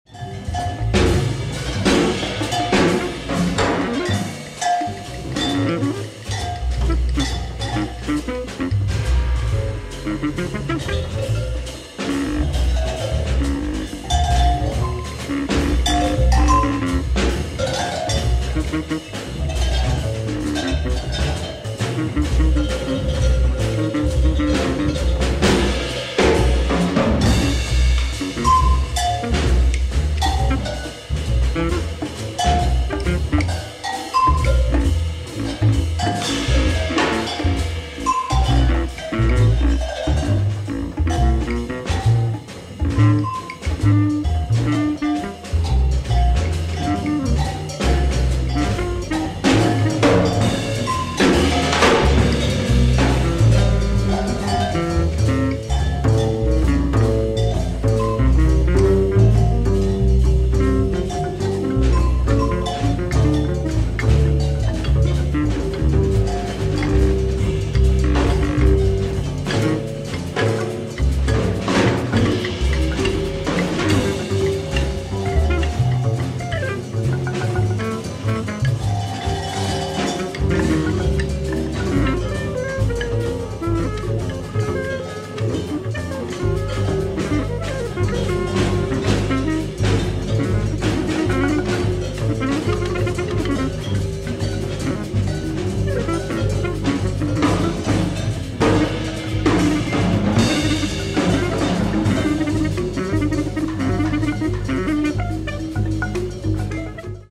French free jazz nugget
keyboards
drums
double bass
flute and percussion